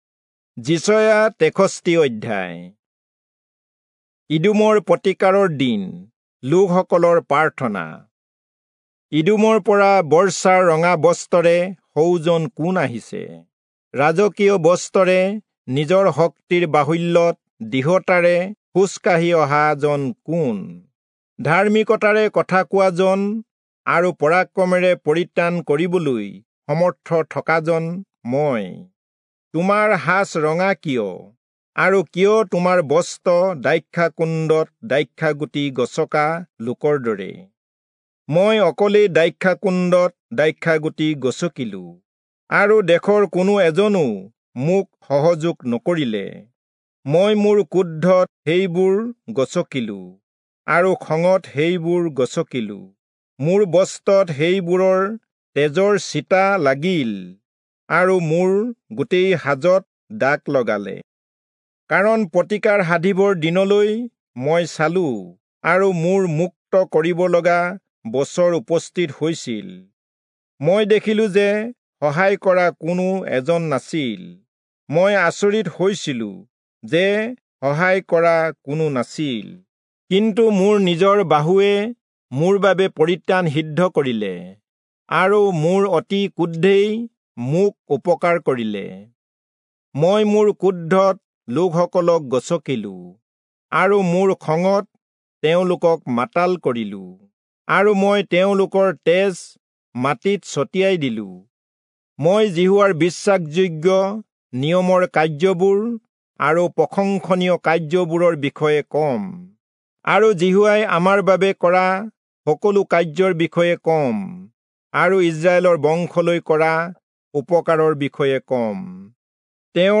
Assamese Audio Bible - Isaiah 10 in Ylt bible version